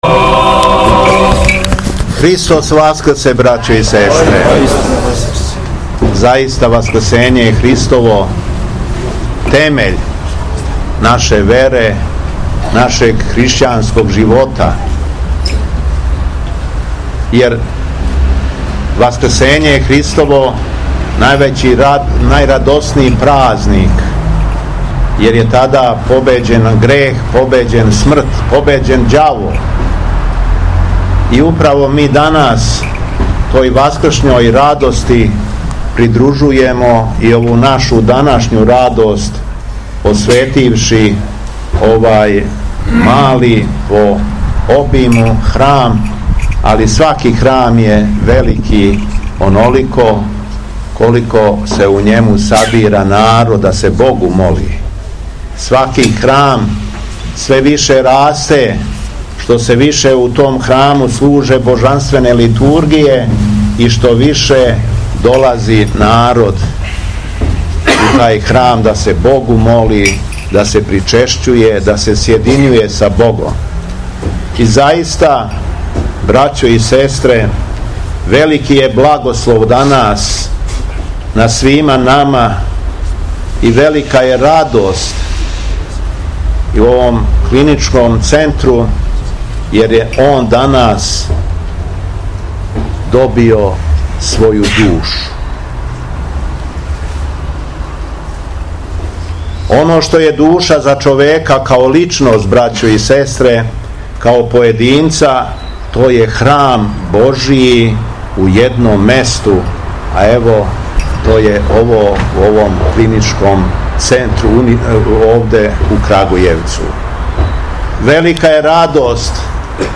ОСВЕЋЕЊЕ ХРАМА СВЕТОГ НЕКТАРИЈА ЕГИНСКОГ ПРИ УНИВЕРЗИТЕТСКОМ КЛИНИЧКОМ ЦЕНТРУ КРАГУЈЕВАЦ - Епархија Шумадијска
Духовна поука Његовог Високопреосвештенства Митрополита шумадијског г. Јована
У суботу, 3. маја 2025. године, Његово Високопреосвештенство Архиепископ крагујевачки и Митрополит шумадијски Господин Јован осветио је храм посвећен Светом Нектарију Егинском у оквиру Универзитетског клиничког центра Крагујевац, где је затим служио Свету Архијерејску Литургију.